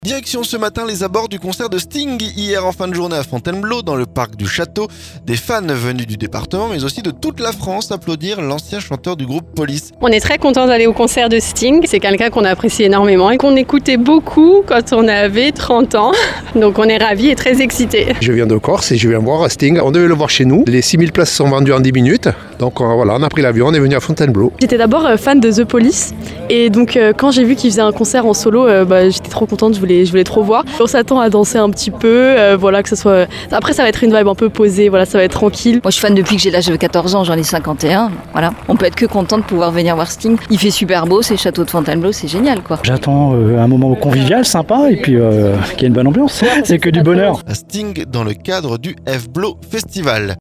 Direction ce matin les abords du concert de Sting hier en fin de journée à Fontainebleau. Dans le parc du château.